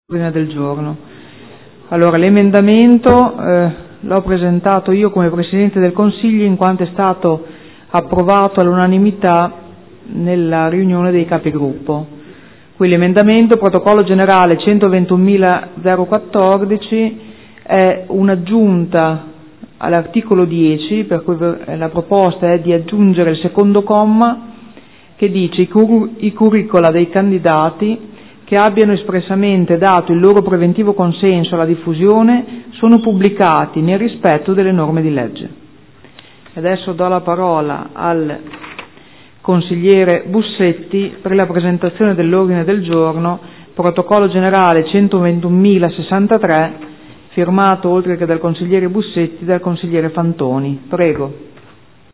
Seduta del 10 settembre.